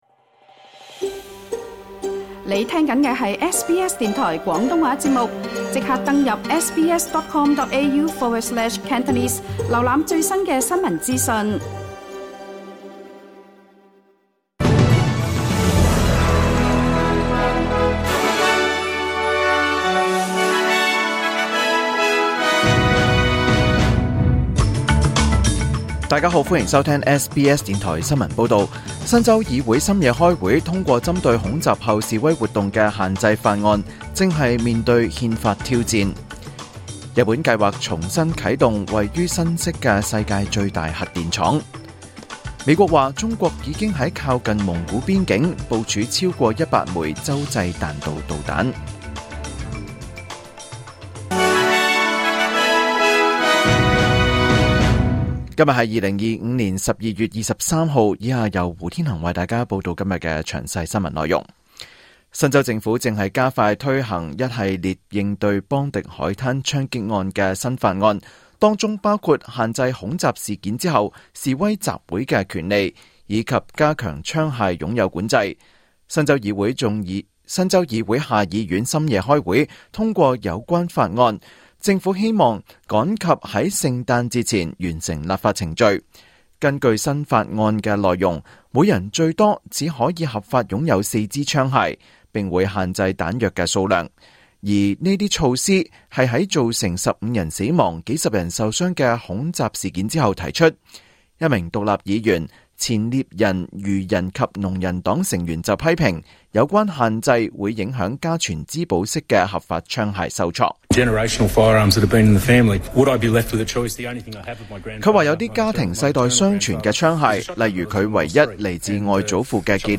2025 年 12 月 23 日 SBS 廣東話節目詳盡早晨新聞報道。